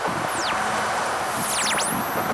nerfs_psynoise12.ogg